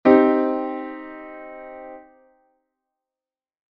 Cマイナートライアド
構成音「Root、短3度、完全5度」
C△のEがE♭になるだけで、少し暗い印象になります。
Cm, C－コード
Cmコード.mp3